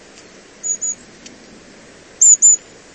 Codibugnolo
Aegithalos caudatus
Un alto ‘sisisi’ e un caratteristico ‘cirrup’.
Cudignolo_Aegithalos_caudatus.mp3